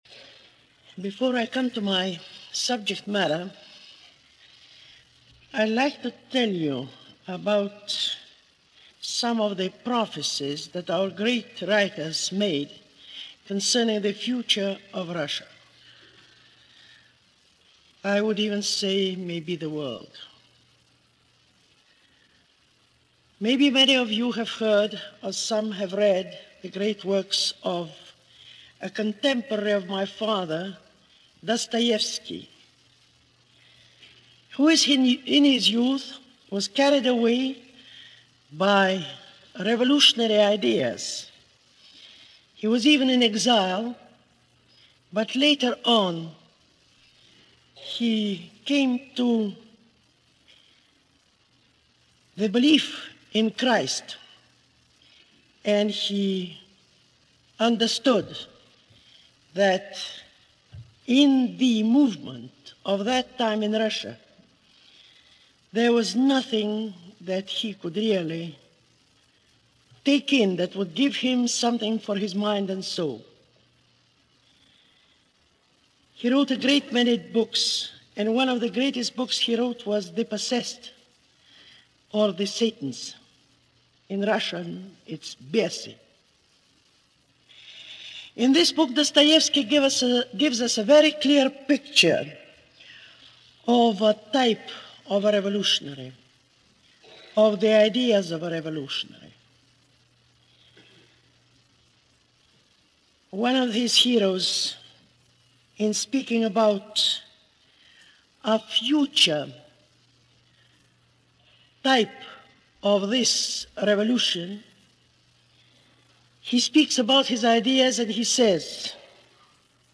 Countess-Tolstoy-Lecture-On-The-Soviet-Union-April-4-1949.mp3